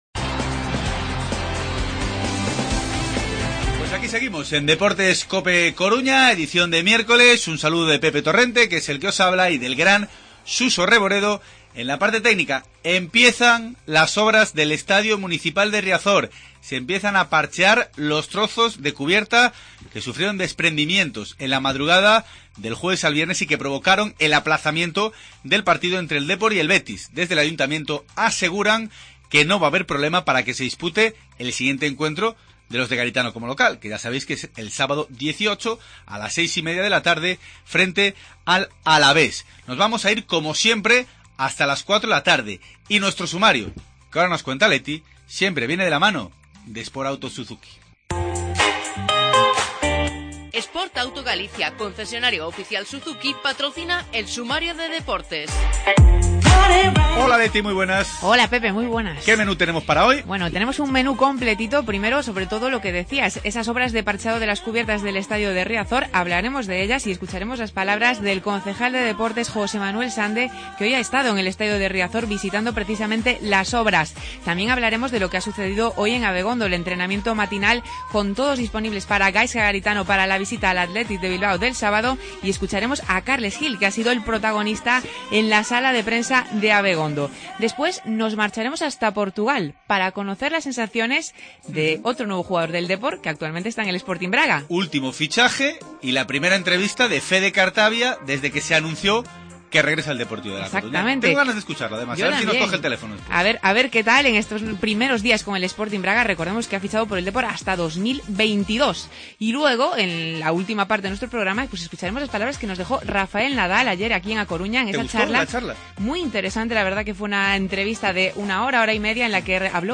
Además, entrevistamos a Fede Cartabia y escuchamos las palabras de Rafa Nadal en A Coruña.